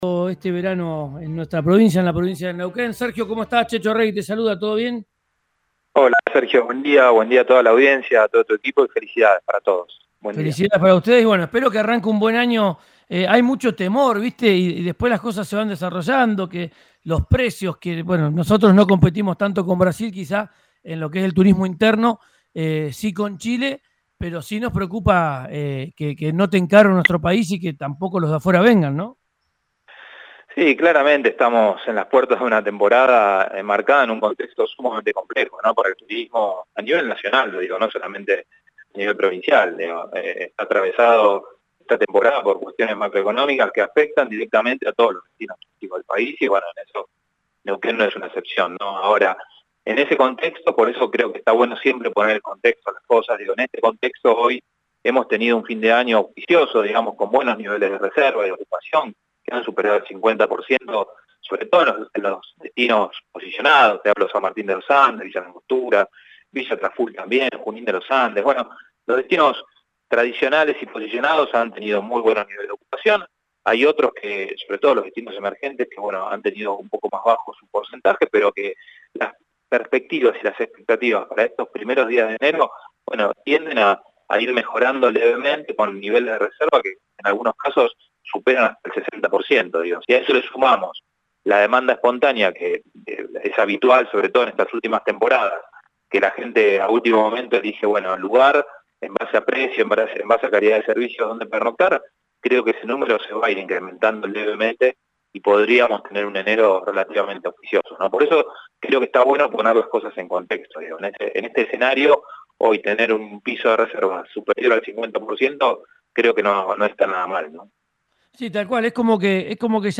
Escuchá a Sergio Sciacchitano, presidente de NeuquenTur, en RADIO RÍO NEGRO: